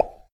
tap.wav